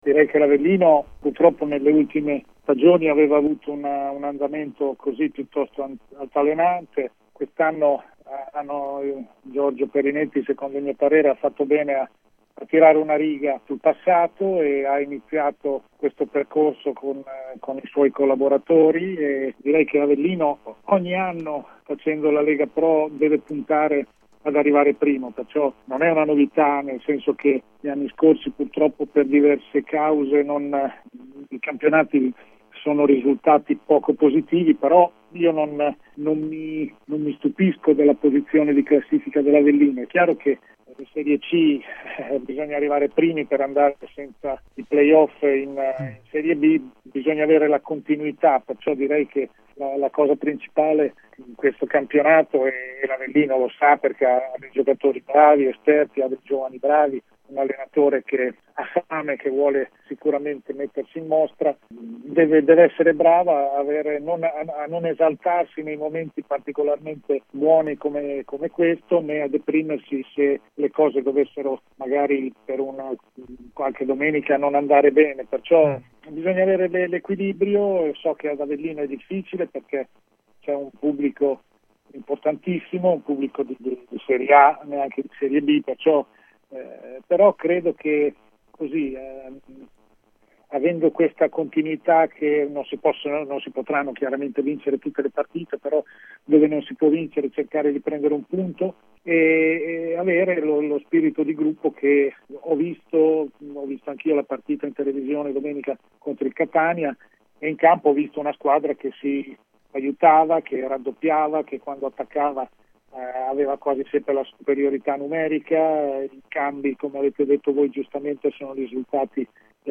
Ospite de Il Pomeriggio da Supereroi di Radio Punto Nuovo